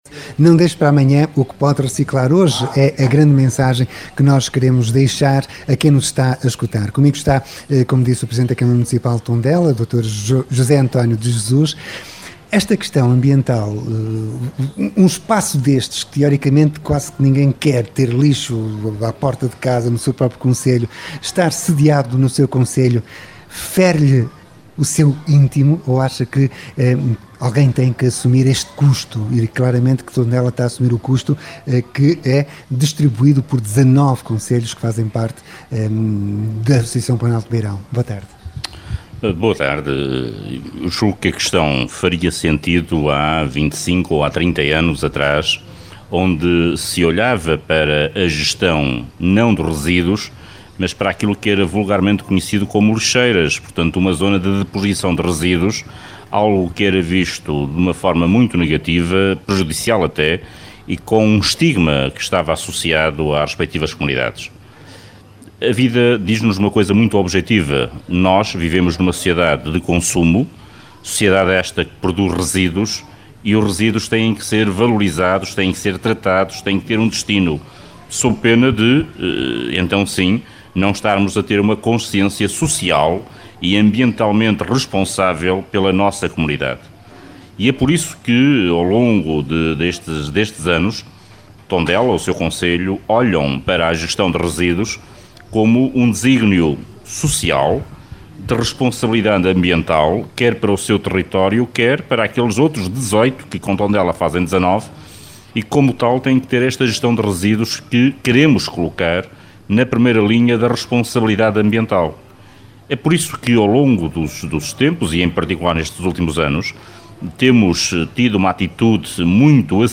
A Rádio Regional do Centro esteve em directo do maior Centro de Tratamento de Resíduos Sólidos Urbanos para assinalar, de forma antecipada, o Dia Mundial do Ambiente. Aqui foi inaugurado o maior mural de arte urbana com a temática ambiental.
Mário Loureiro, presidente da Câmara Municipal de Tábua e presidente executivo da Associação de Municípios do Planalto Beirão: